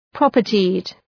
Προφορά
{‘prɒpərtıd}